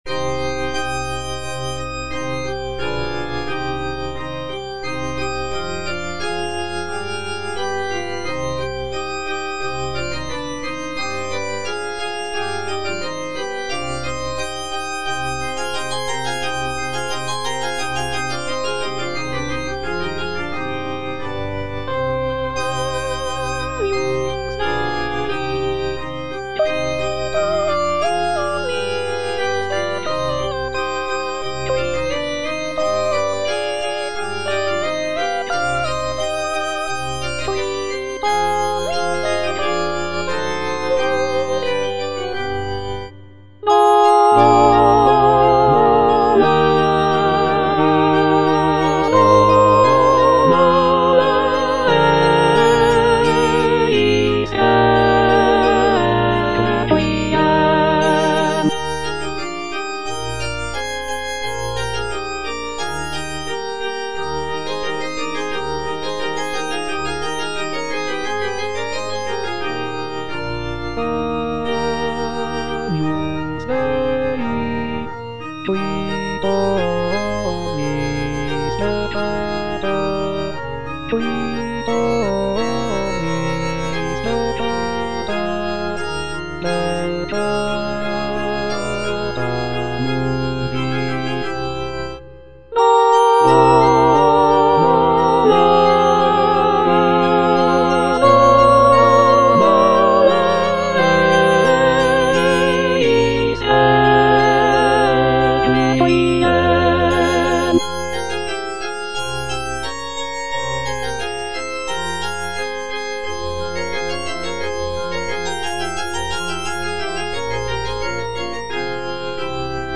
Soprano (Emphasised voice and other voices) Ads stop
choral composition